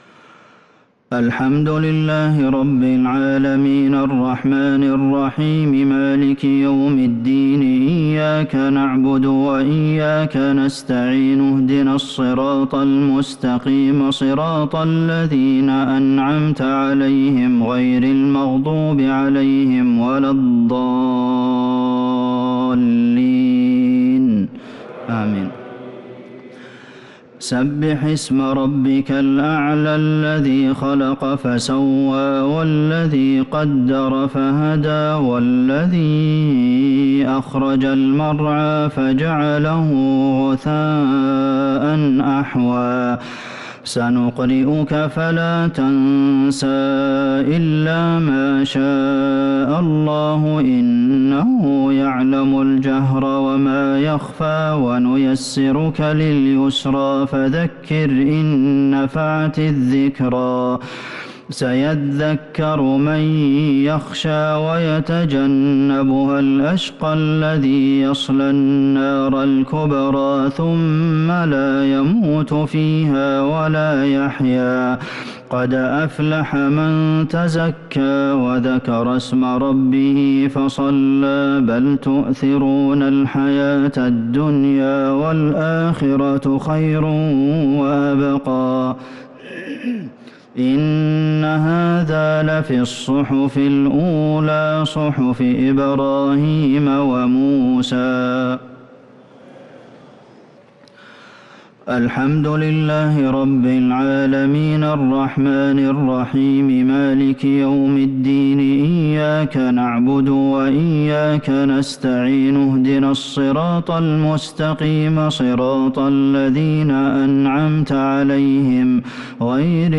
الشفع و الوتر ليلة 22 رمضان 1443هـ | Witr 22 st night Ramadan 1443H > تراويح الحرم النبوي عام 1443 🕌 > التراويح - تلاوات الحرمين